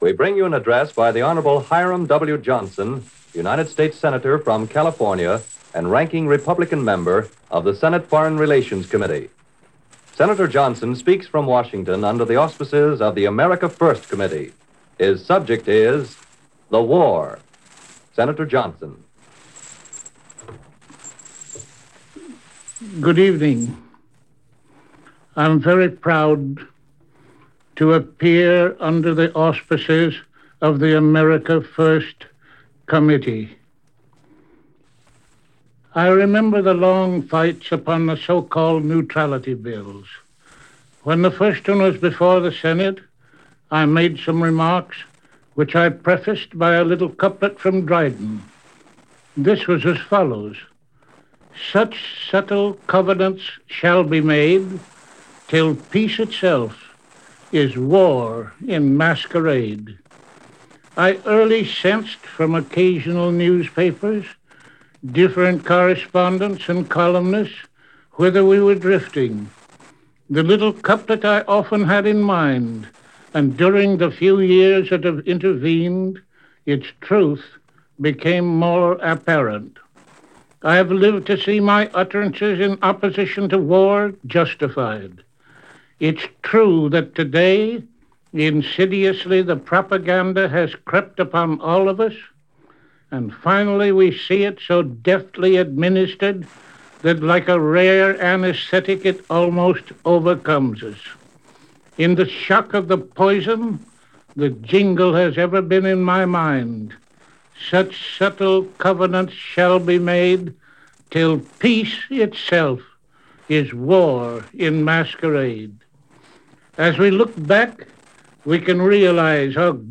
An Address By Sen. Hiram Johnson - America First Committee - May 31, 1940 - Past Daily Reference Room